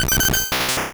Cri de Taupiqueur dans Pokémon Rouge et Bleu.